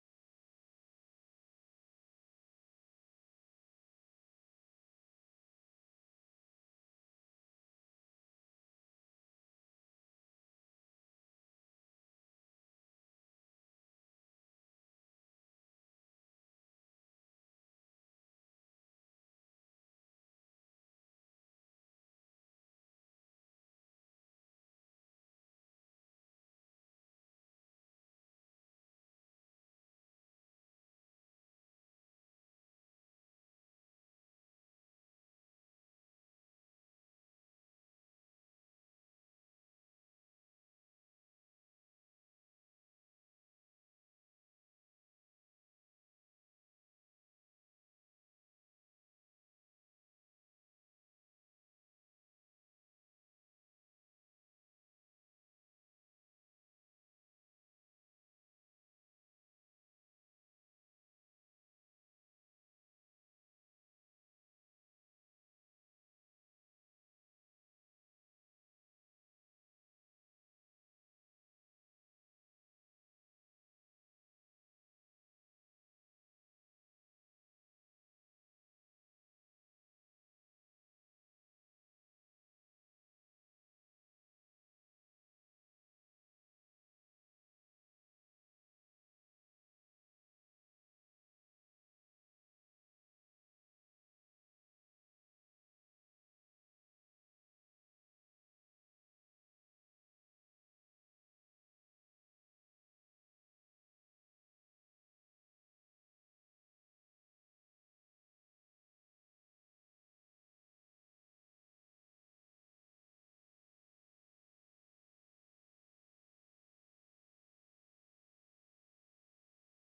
July 20 2025 Family Worship Center Praise and Worship